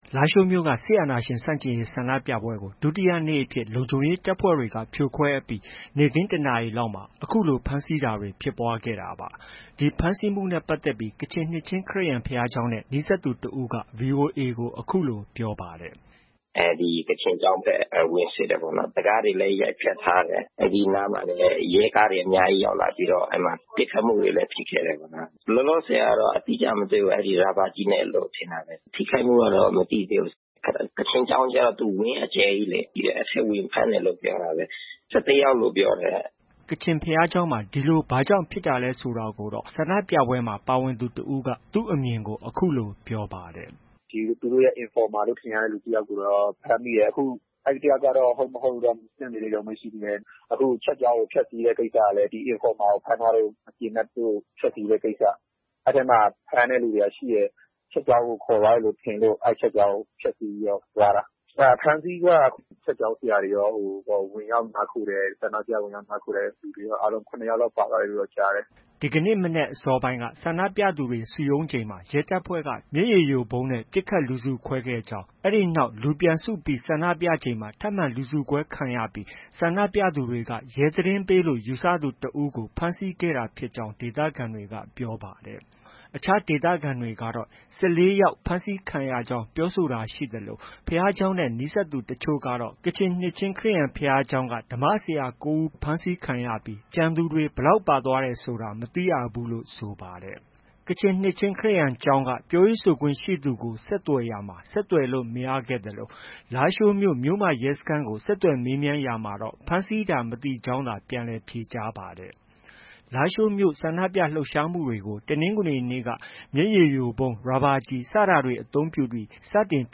ဒီဖမ်းဆီးမှုနဲ့ ပတ်သက်ပြီး ကချင်နှစ်ချင်းခရစ်ယာန် ဘုရားကျောင်းနဲ့ နီးစပ်သူတစ်ဦးက ဗွီအိုအေကို အခုလို ပြောပါတယ်။
ကချင်ဘုရားကျောင်းမှာ ဒီလို ဘာကြောင့် ဖမ်းဆီးတာလဲဆိုတာကိုတော့ ဆန္ဒပြပွဲမှာ ပါဝင်သူတစ်ဦးက သူ့အမြင်ကို အခုလို ပြောပါတယ်။